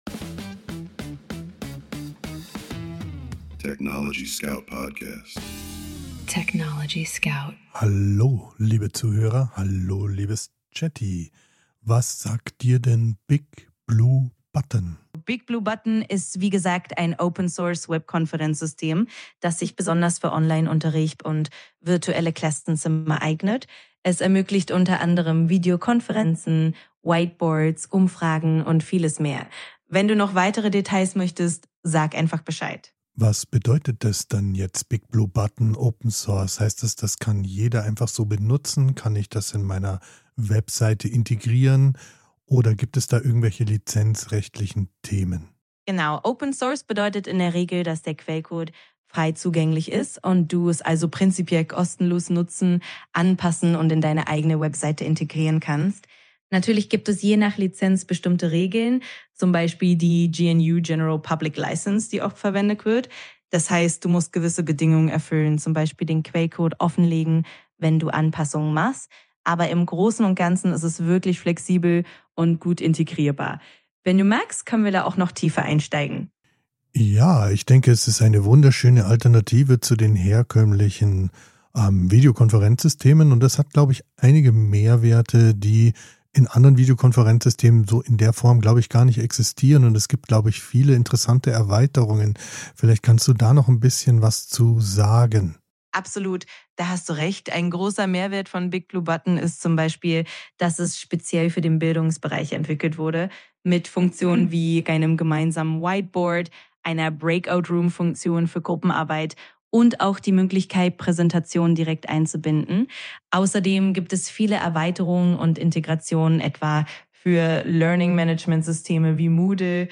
Mensch und KI sprechen miteinander – nicht